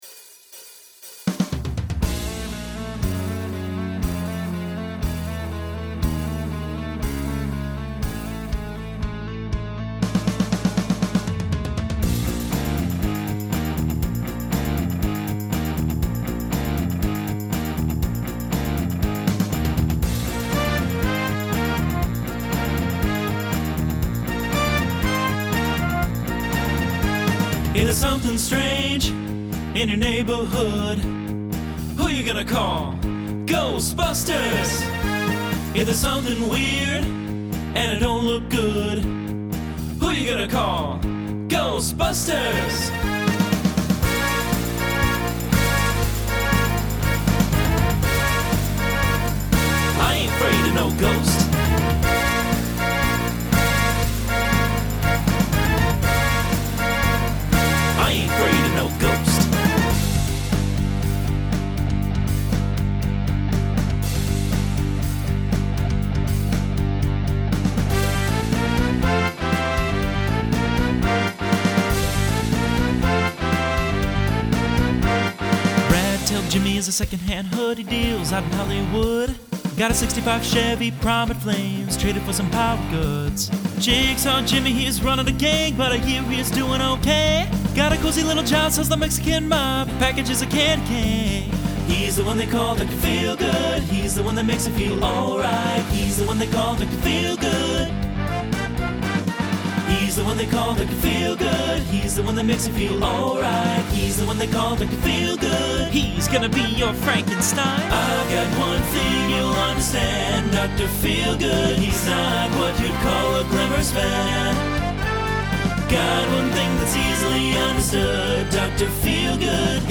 Genre Broadway/Film , Rock Instrumental combo
Transition Voicing TTB